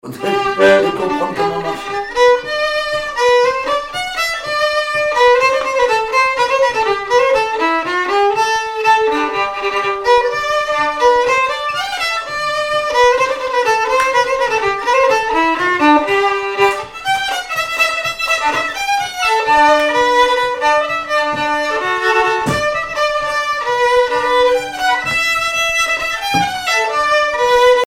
danse : marche
violon
Pièce musicale inédite